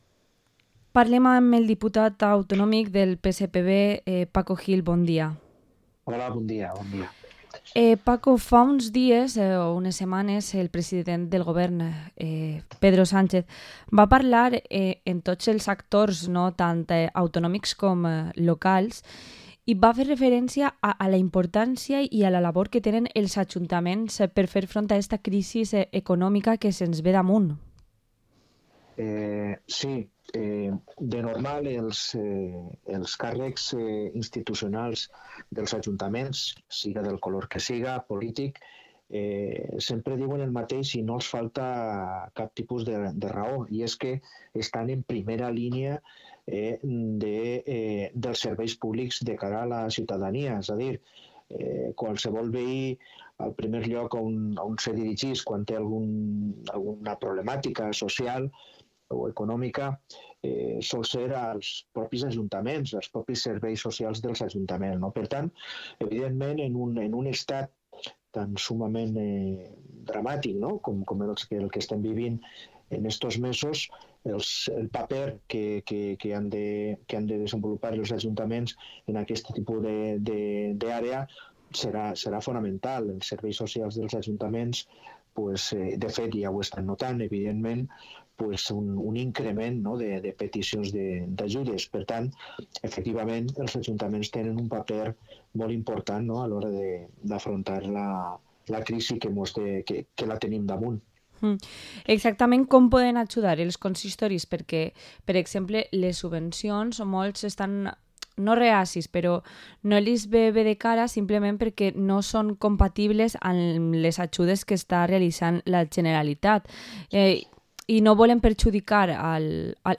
Entrevista al diputado autonómico del PSPV-PSOE, Paco Gil